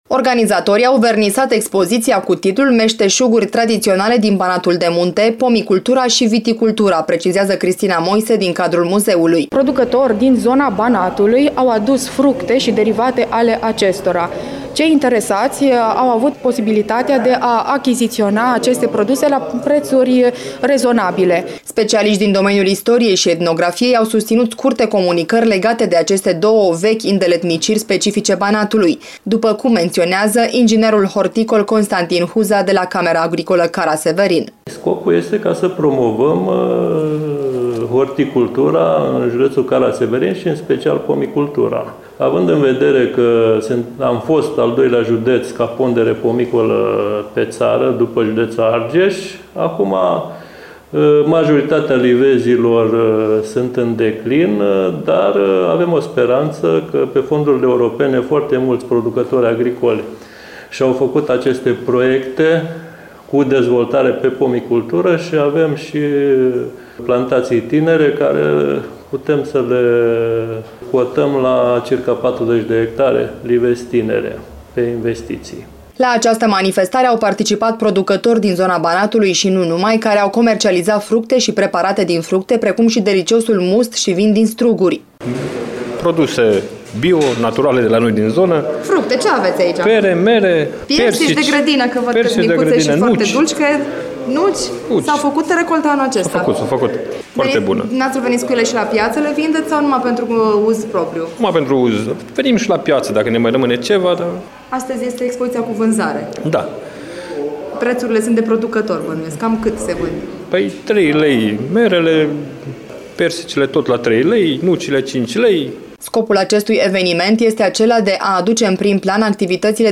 Cu această ocazie, organizatorii au vernisat expoziţia cu titlul “Meşteşuguri tradiţionale din Banatul de munte: pomicultura şi viticultura”. Astfel, specialişti din domeniul istoriei şi etnografiei au susţinut scurte comunicări legate de aceste două vechi îndeletniciri specifice Banatului.
Atmosfera a fost completată de un spectacol de muzică şi dansuri populare care s-a desfăşurat în curtea muzeului.